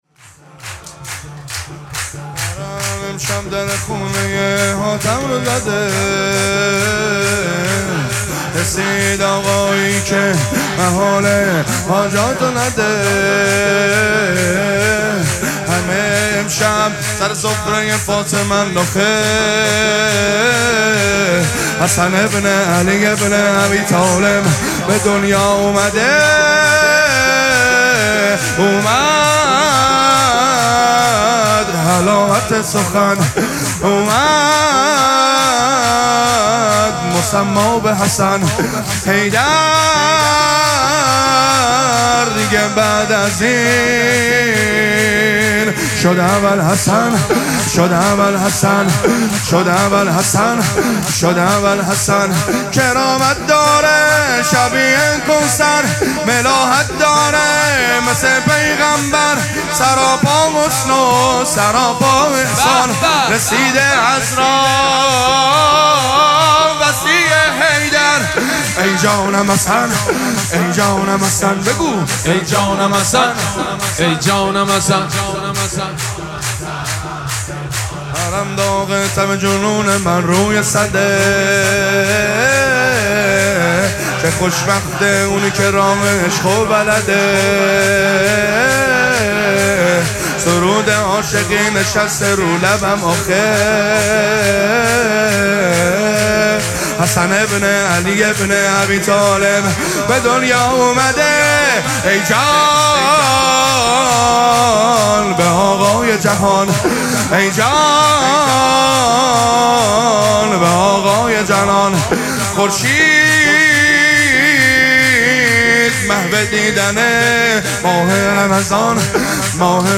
مراسم جشن شب ولادت امام حسن مجتبی(ع)
حسینیه ریحانه الحسین سلام الله علیها
سرود